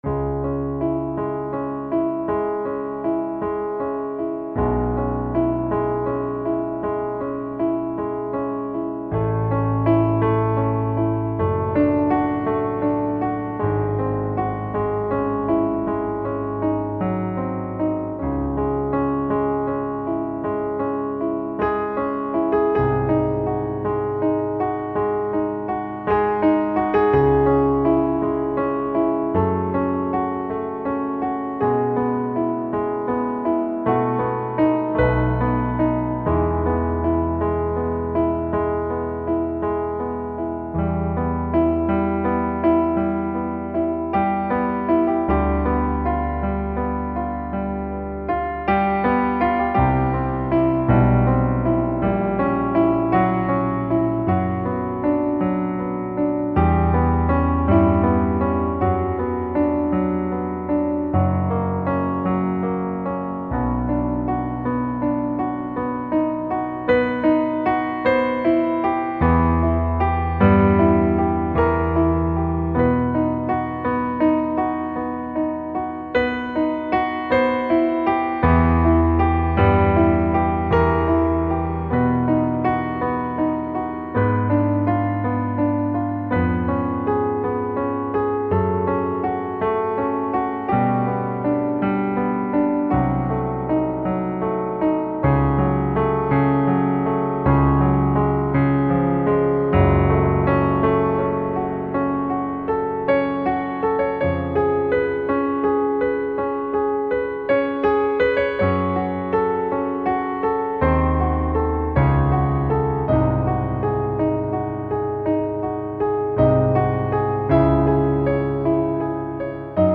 Pure piano
(classical)
It is played on a YAMAHA CLP-240 piano.